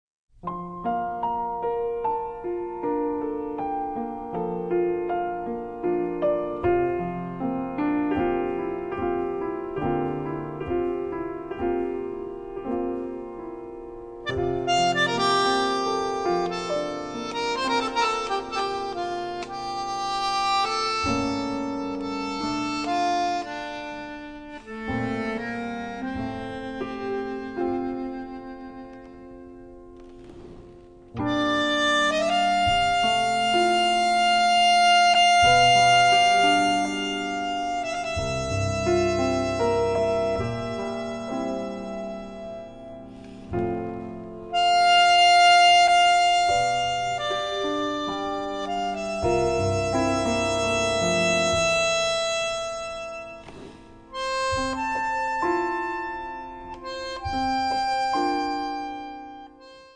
piano
bass tr. 1,2,3,5,6,7,8
drums tr. 1,2,3,5,6,7,8,10,11